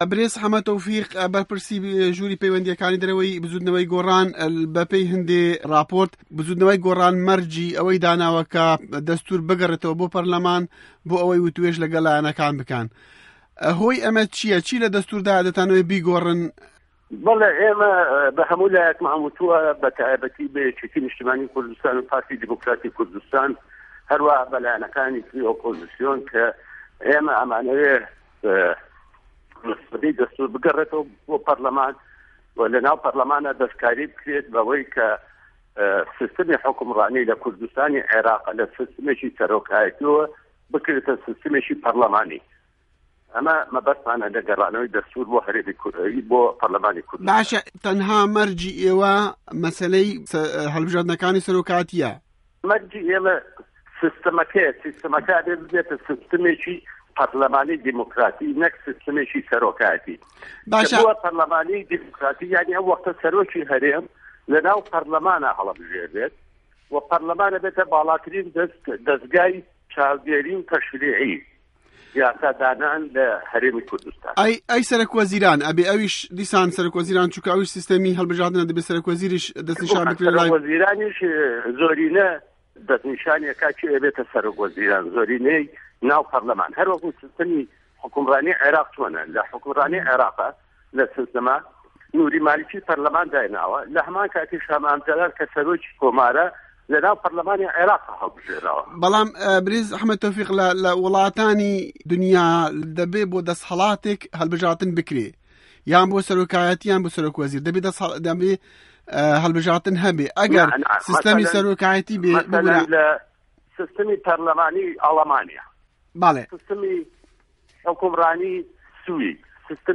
وتووێژ له‌گه‌ڵ محه‌مه‌د تۆفیق ڕه‌حیم